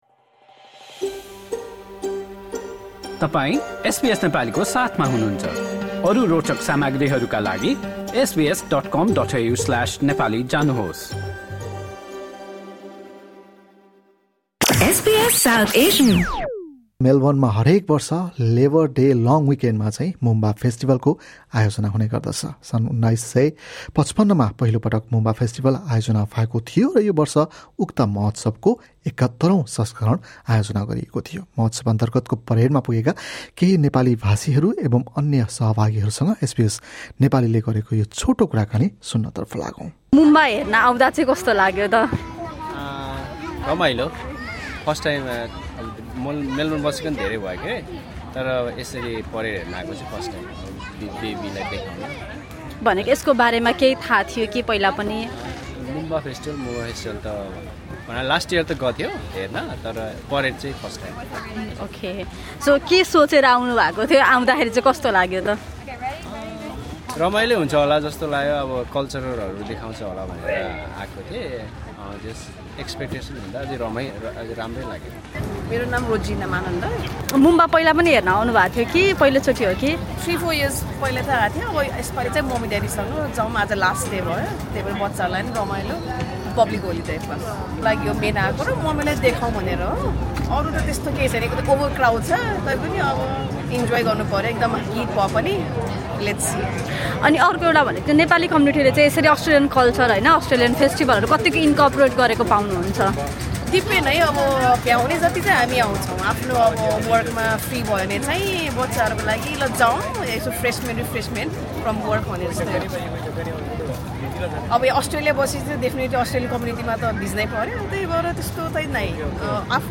Moomba Parade performer and some Nepali-speaking participants spoke to SBS Nepali about their experience of Moomba Parade.